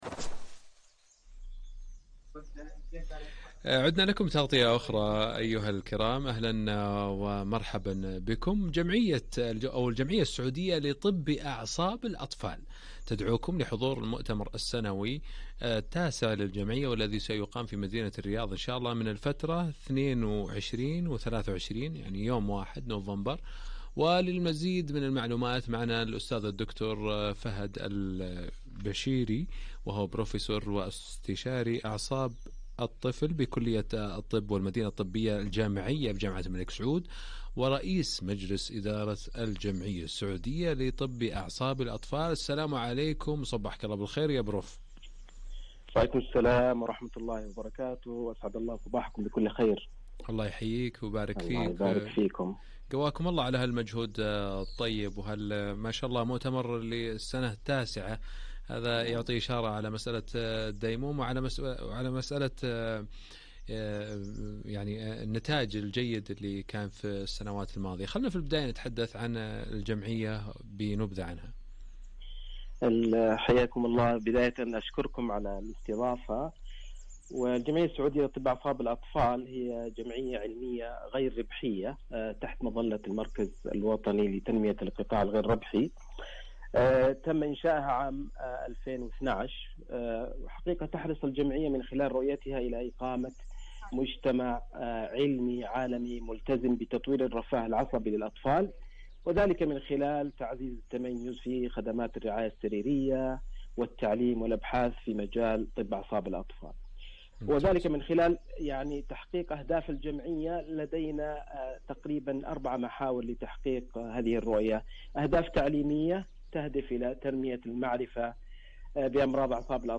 radio interview
SPNS-Radio-Interview.mp3